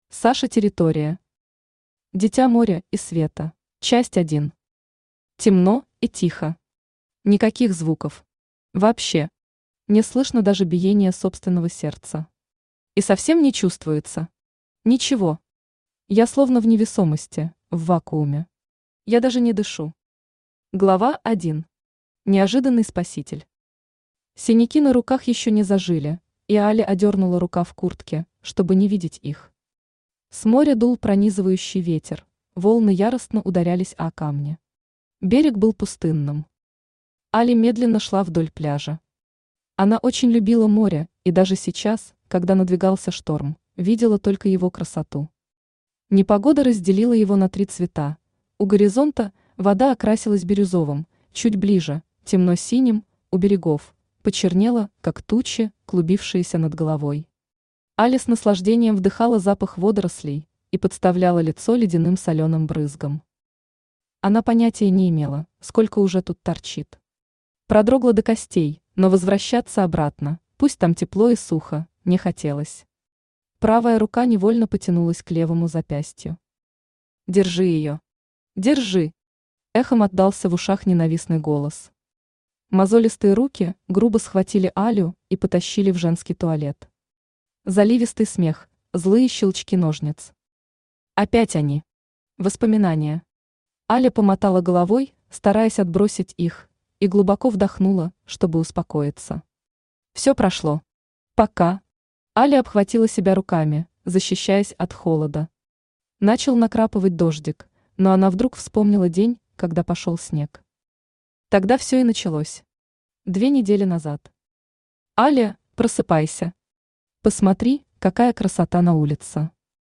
Aудиокнига Дитя моря и света Автор Саша Тер Читает аудиокнигу Авточтец ЛитРес.